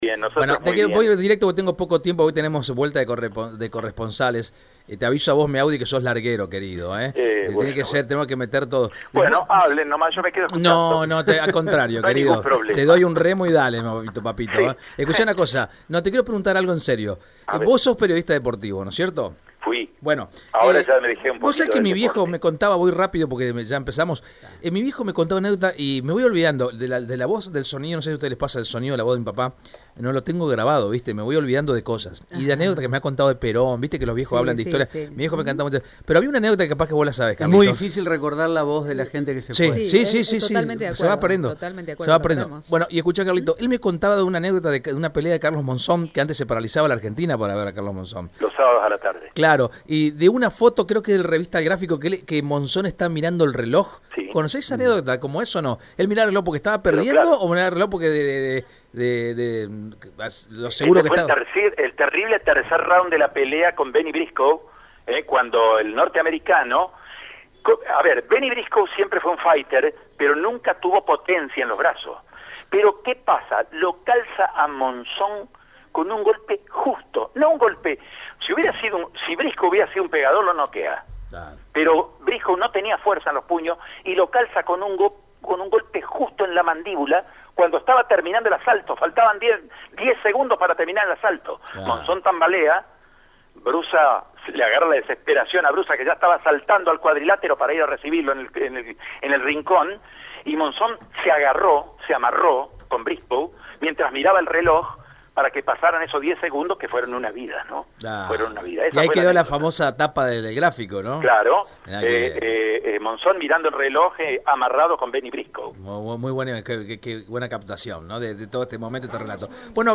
Los periodistas de Radio EME corresponsales de las ciudad de Rafaela, Ceres, San Cristóbal, Rosario, Reconquista, San Javier, Vera y San Justo informaron en el «Pase de Radio EME» (lunes a viernes de 9 a 9.30) la situación angustiante que viven las cuentas municipales y comunales.